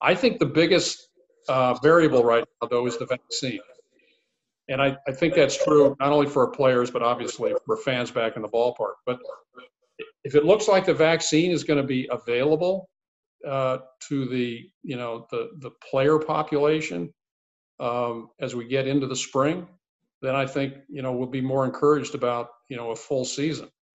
Sandy Alderson made 2 major announcements in his Zoom call with reporters yesterday–one was expected and the other was a bit surprising.